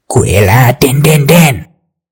QUILLADIN.mp3